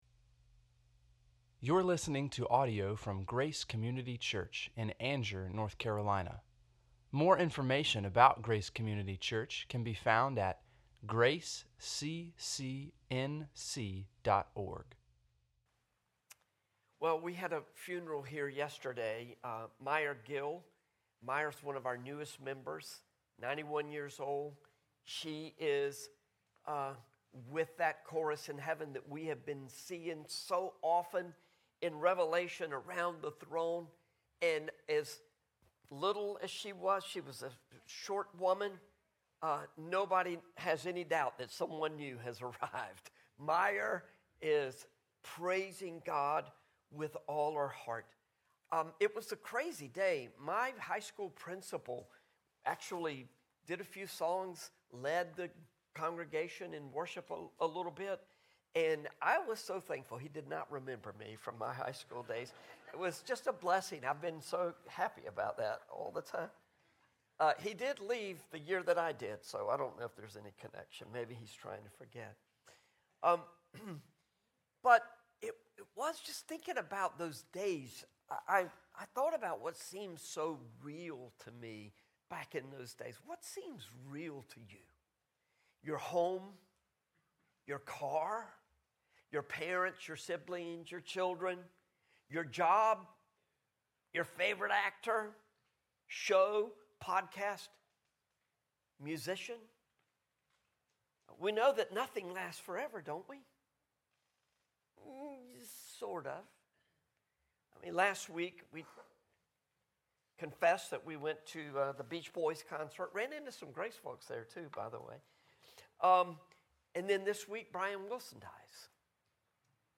sermon-6-15-25.mp3